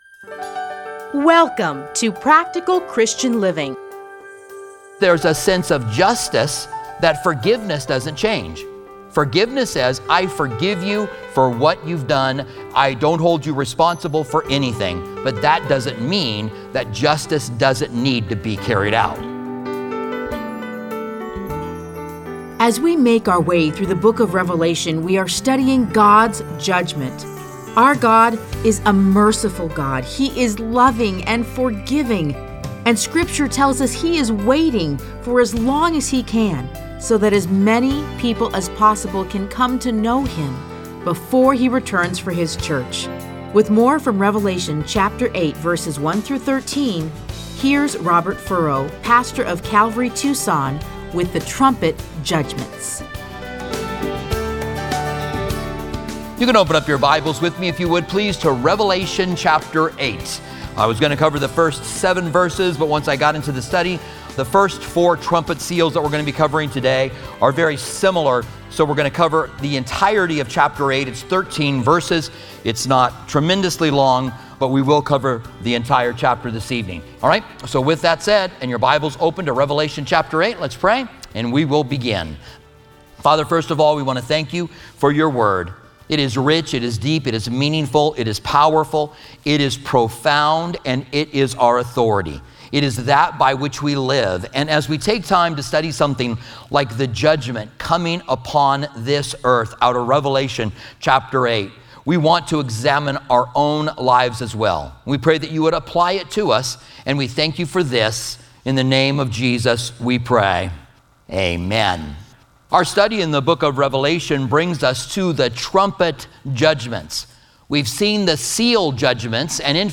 Listen to a teaching from Revelation 8:1-13.